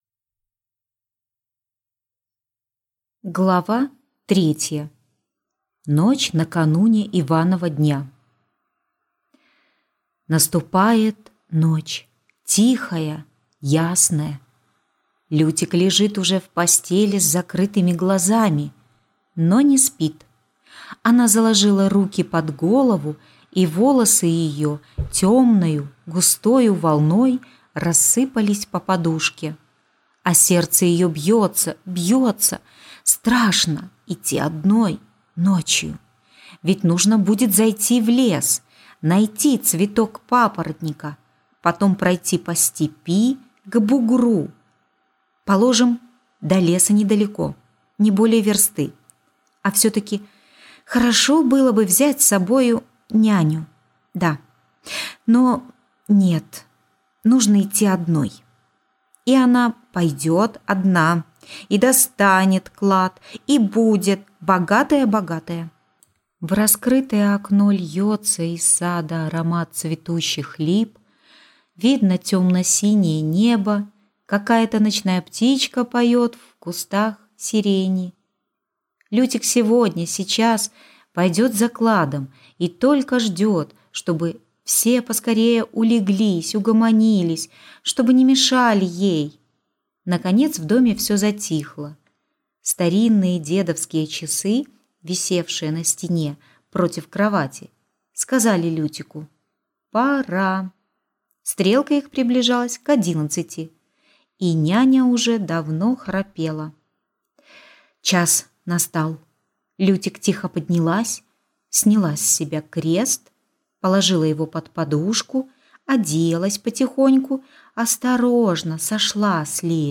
Аудиокнига Лютик | Библиотека аудиокниг